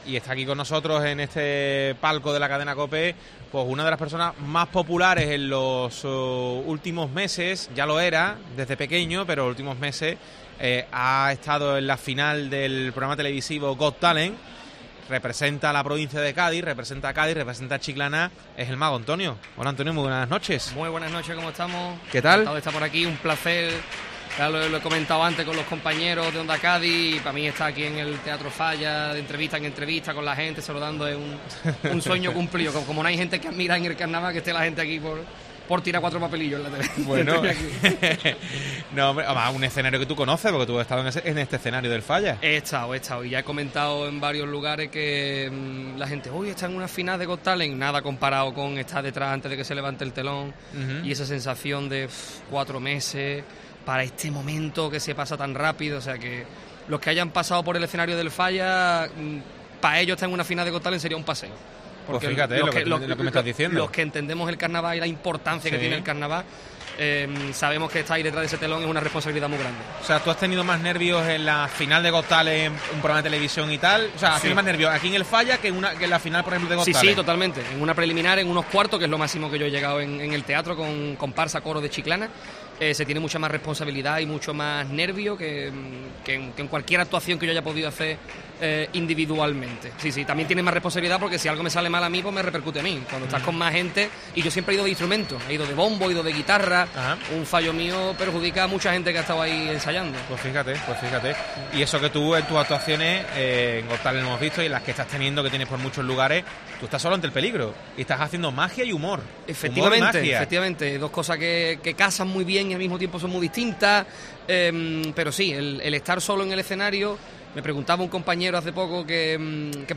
desde el Falla